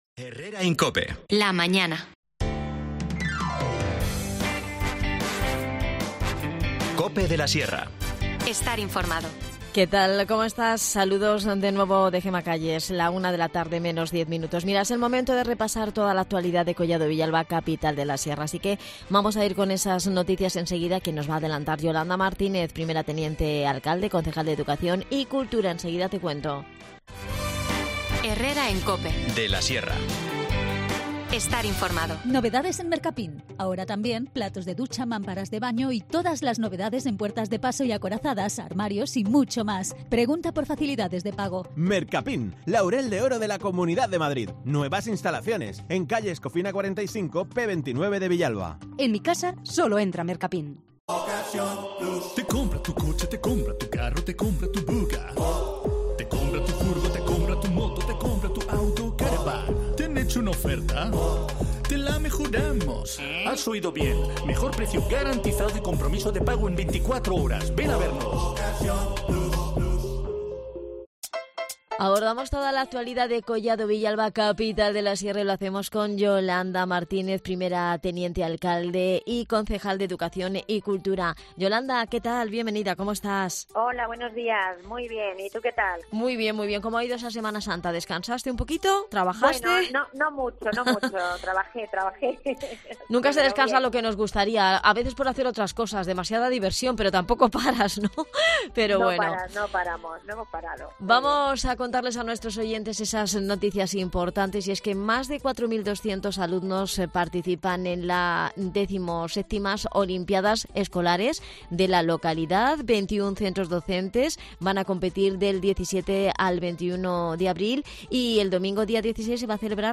Hablamos con Yolanda Martínez, primera teniente alcade y concejal de Educación y Cultura sobre esta y otras noticias relacionadas con el mundo de la educación, el empleo y el entretenimiento.